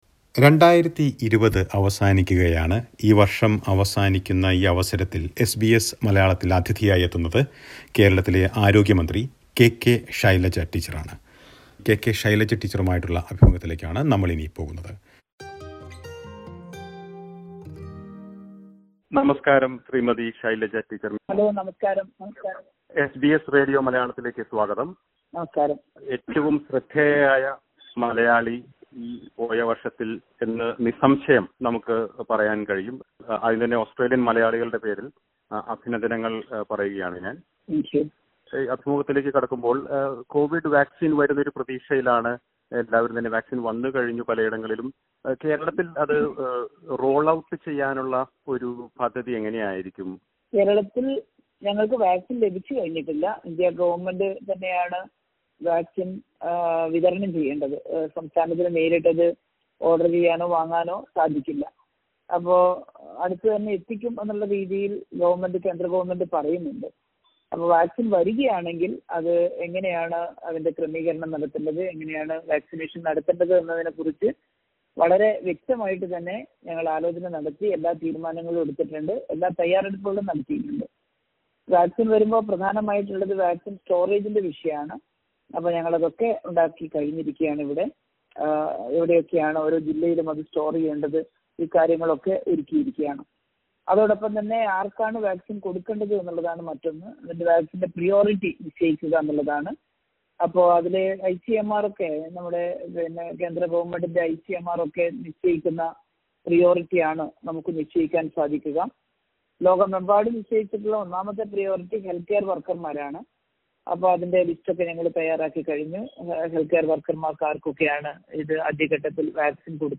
Kerala's Covid response in 2020: Interview with Kerala Health Minister KK Shailaja
Kerala's Health Minister KK Shailaja speaks to SBS Malayalam about Covid response and main challenges during the year 2020.